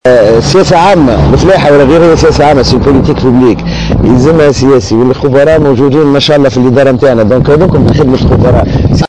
وقال في تصريح إعلامي على هامش لقاء رئيس الحكومة المكلف يوسف الشاهد مساء اليوم بعدد من أعضاء فريقه الحكومي إن السياسة العامة للدولة تقتضي تنصيب رجل سياسة وأن الخبراء موجودون في الإدارات، وفق تعبيره.